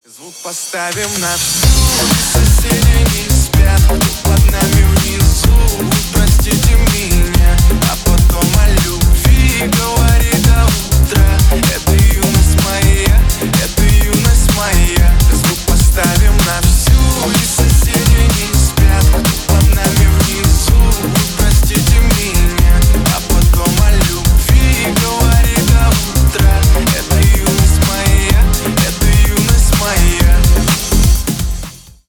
• Качество: 320 kbps, Stereo
Ремикс
Поп Музыка